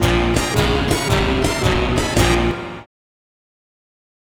Horn Lik 111-A#.wav